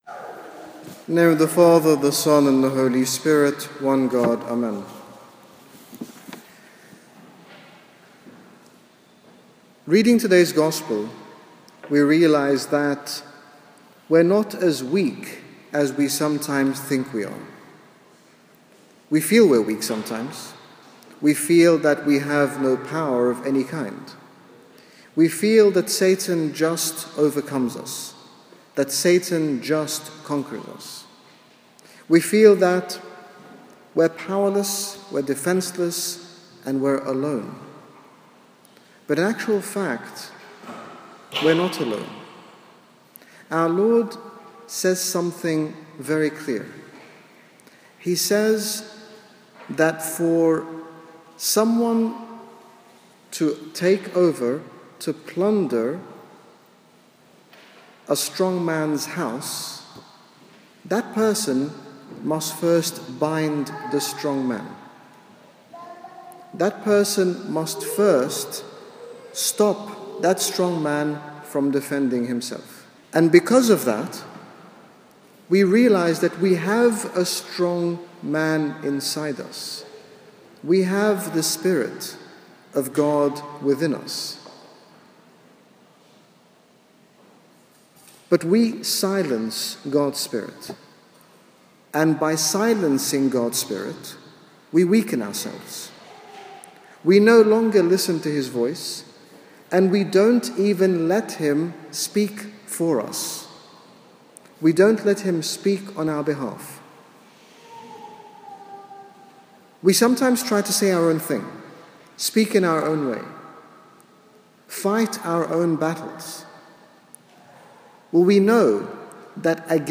In this short sermon, His Grace Bishop Angaelos, General Bishop of the Coptic Orthodox Church in the United Kingdom, speaks about the strength that we have as a result of the Lord abiding in us, reassuring us that we are stronger than we think we are.